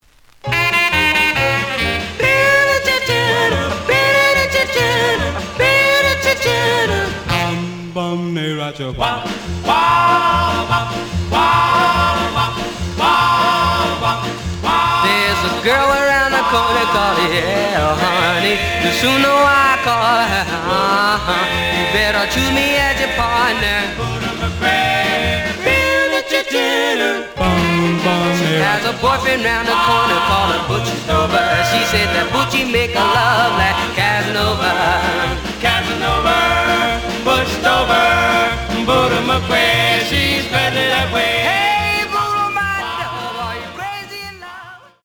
The audio sample is recorded from the actual item.
●Format: 7 inch
●Genre: Rhythm And Blues / Rock 'n' Roll
Edge warp.